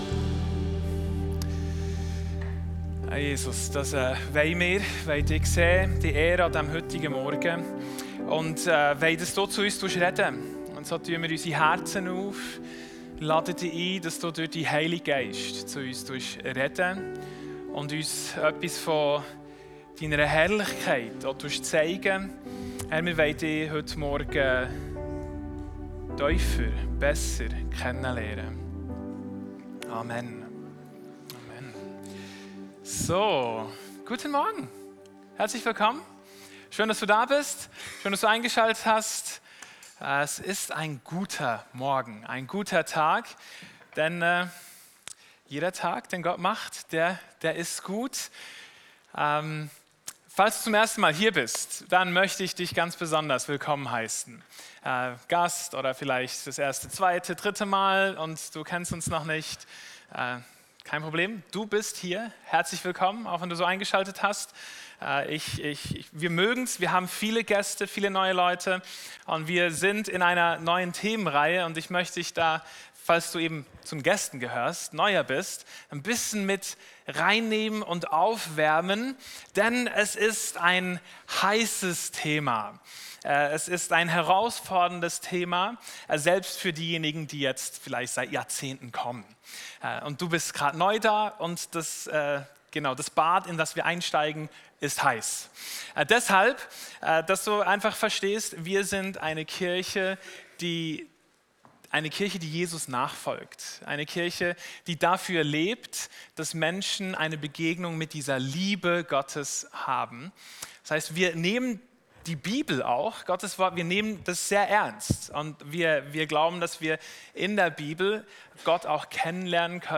Audio+Podcast+Predigt.m4a